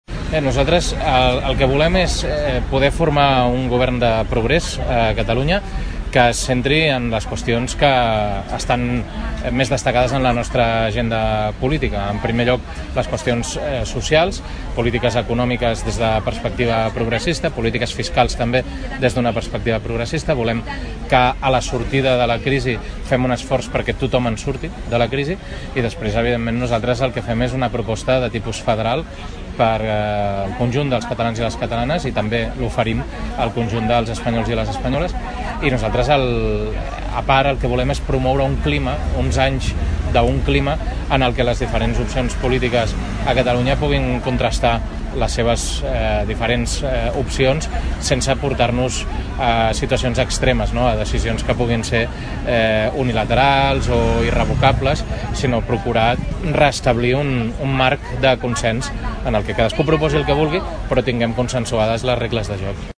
En el marc del mercat dels diumenges, el dirigent socialista va aprofitar per parlar amb el visitants amb la voluntat d’explicar els objectius del PSC per aquestes eleccions.